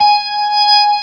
55o-org20-G#5.wav